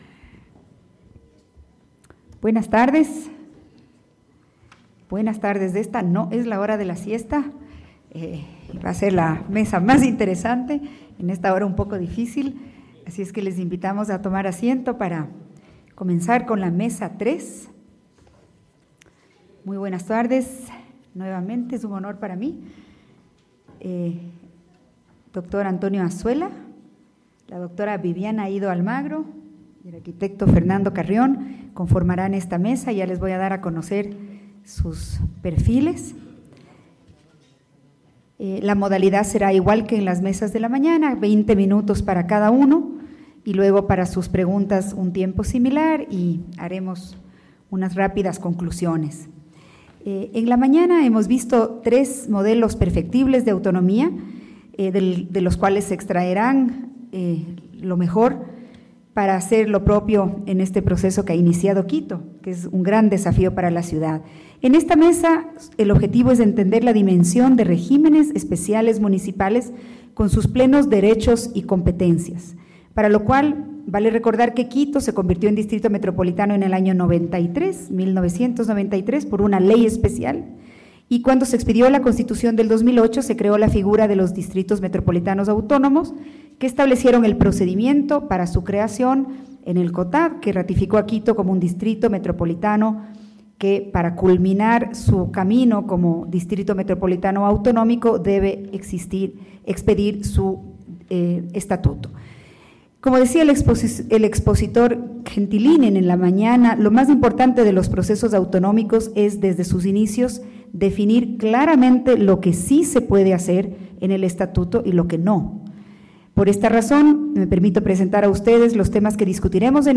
Seminario Internacional: Capitales latinoamericanas: autonomía y desarrollo, 22 y 23 de agosto de 2019. Hemiciclo FLACSO Ecuador.